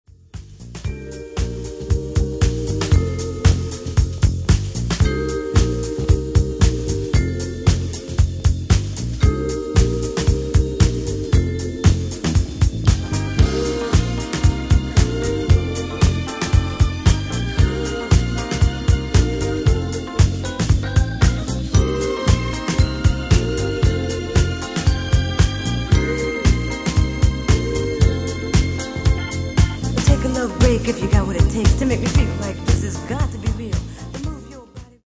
Genre:   Disco Soul